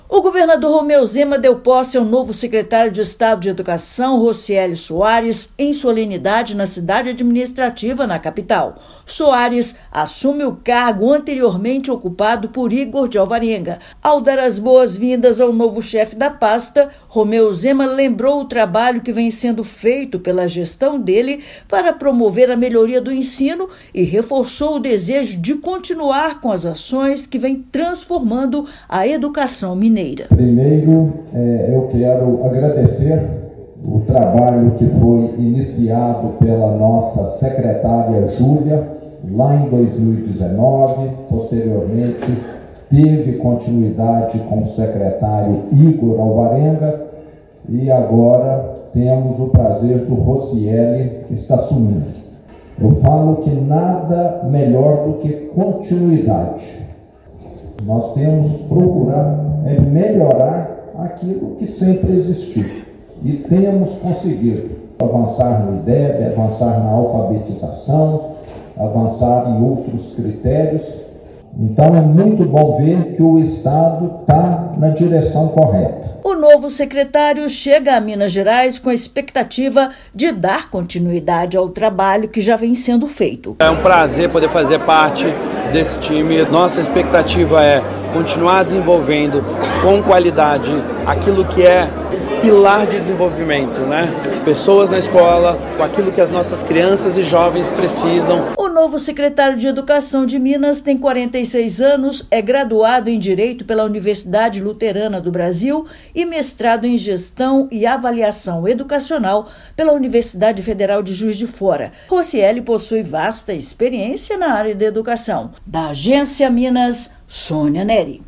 [RÁDIO] Governador empossa novo secretário de Educação de Minas Gerais
Rossieli Soares já foi ministro da área em 2018 e comandou secretarias estaduais de Educação de São Paulo, Amazonas e Pará. Ouça matéria de rádio.